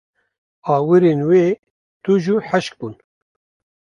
Pronunciado como (IPA)
/hɪʃk/